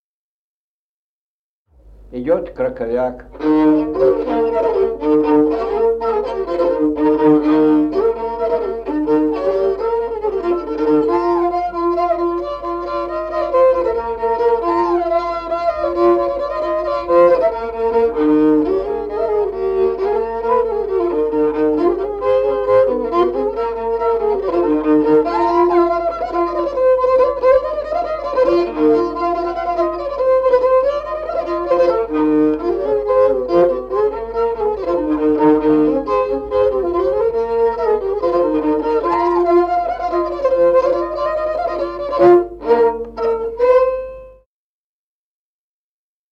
Музыкальный фольклор села Мишковка «Краковяк», репертуар скрипача.